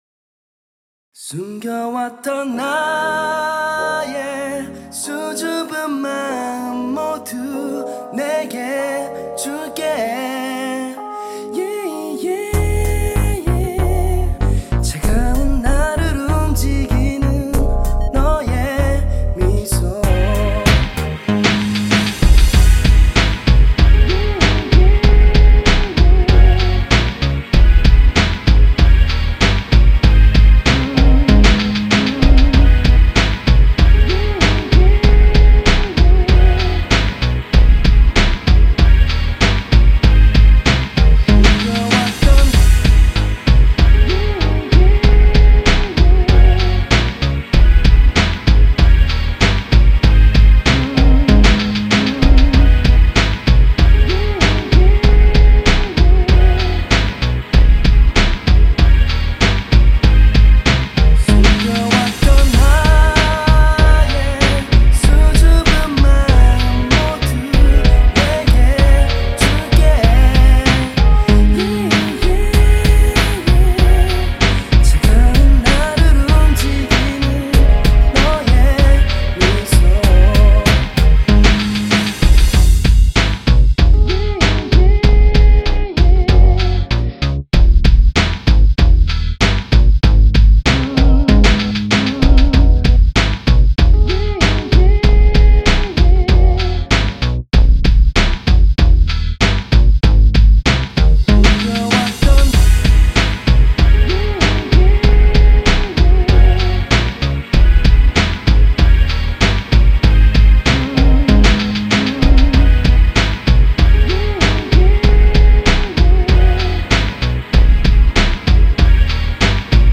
드럼이랑 밑바닥에 깔린 뭔가 왜곡된것같은 패드랑 참 좋네요
베이스를 신시베이스로 깔끔하게 찍는거보다
와우-ㅋ 전체적인 사운드가 올드스쿨틱하네여 ㄷㄷㄷ 잘들었습니다 ^^